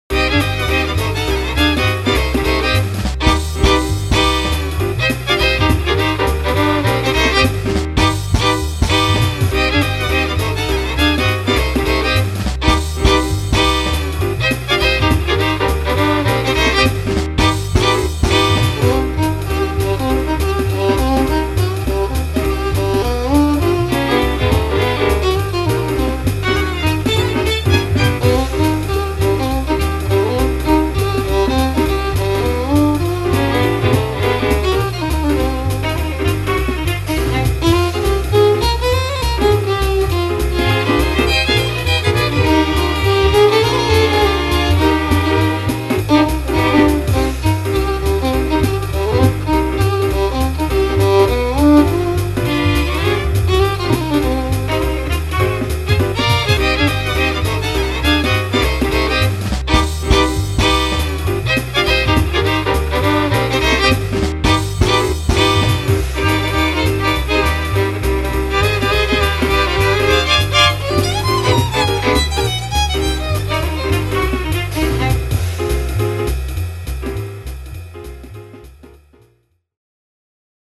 mini bigband de violon
version maquettée avec rythmique midi